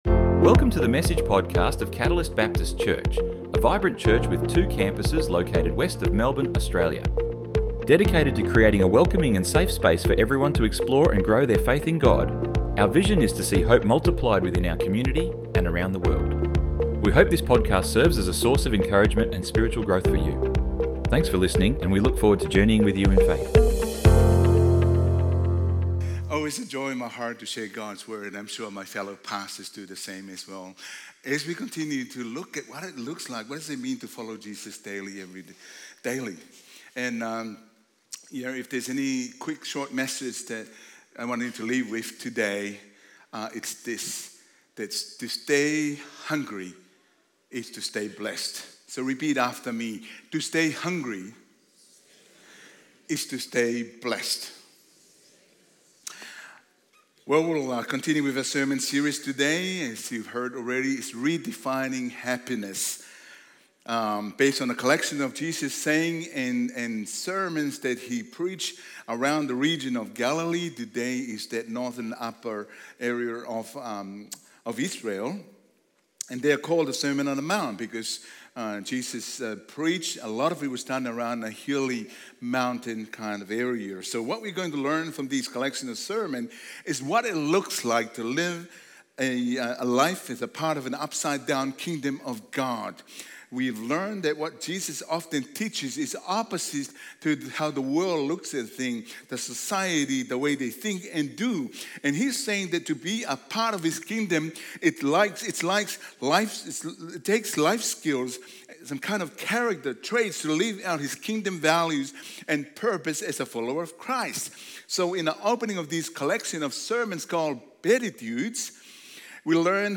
Download Download Sermon Notes 02-LG-notes-RedefHappiness-Whos-Happy-and-Whos-Not.pdf To experience God's true blessings requires certain types of character traits.